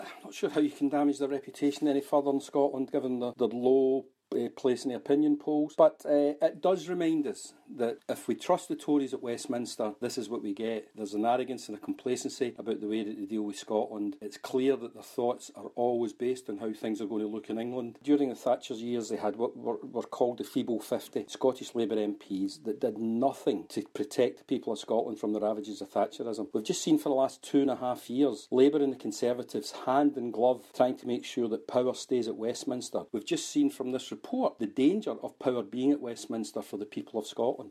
SNP MSP Jamie Dornan says Scots cannot put their trust in the Conservatives, or Labour: